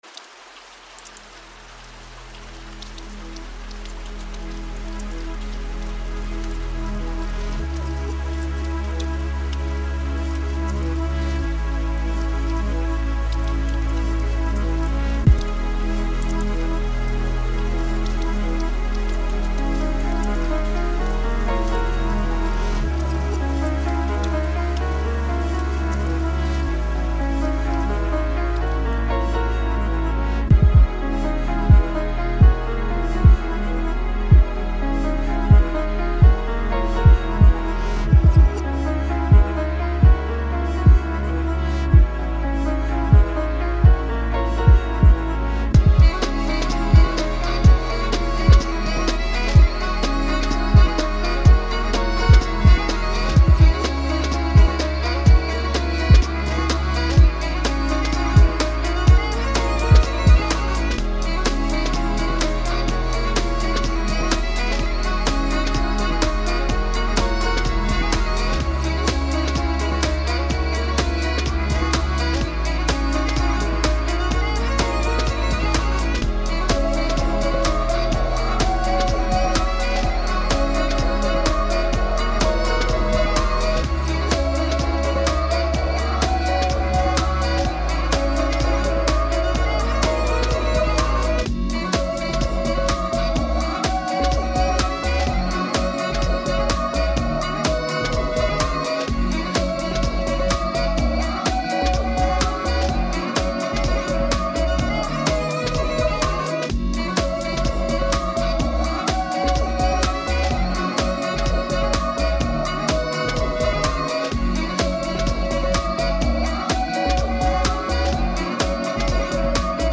Tempo 126BPM (Allegro)
Genre Chill House
Type Instrumental
Mood Chilled